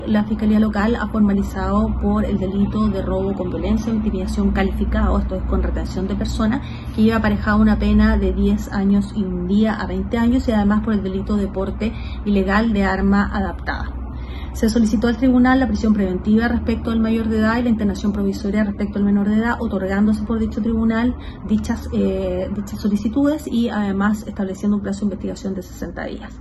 Por ello se dictaminó la prisión preventiva para el detenido mayor de edad y la internación provisoria del menor, confirmando 60 días de investigación, lo que fue detallado por la fiscal de Osorno, María Angélica de Miguel.